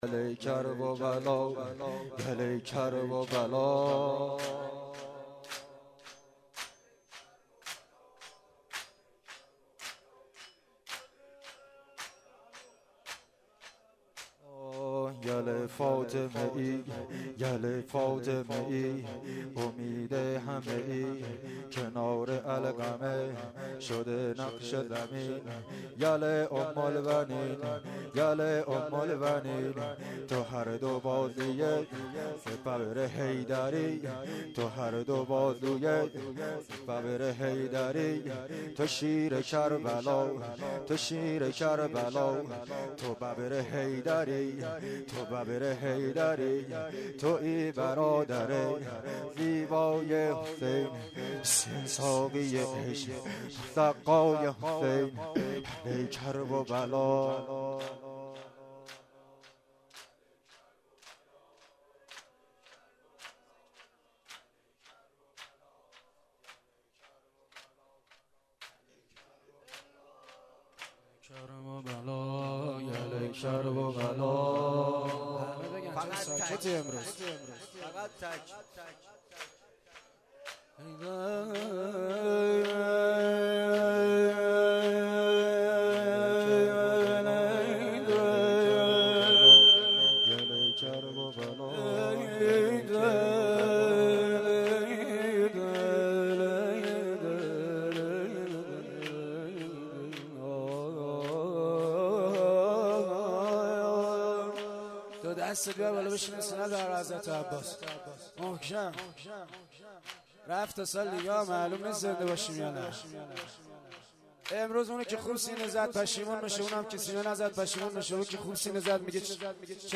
زمینه(تک)_ ( یل فاطمه ای